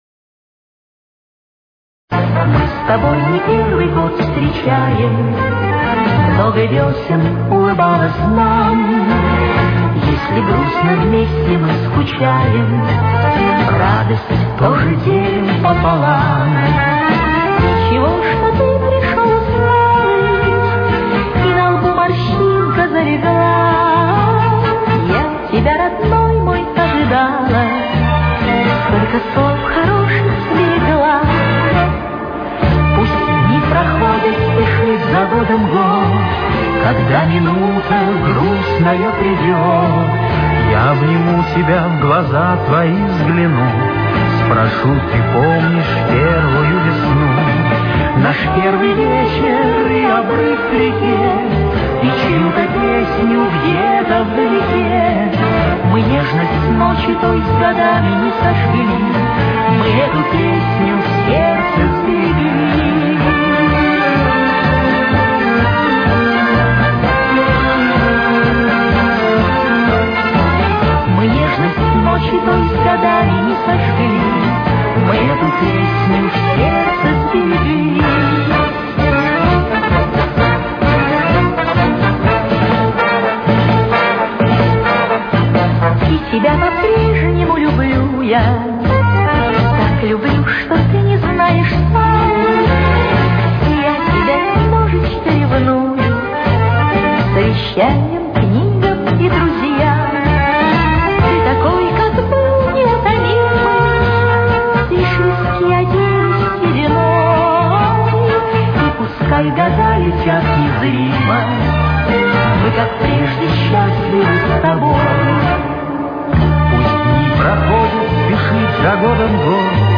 Ре минор. Темп: 129.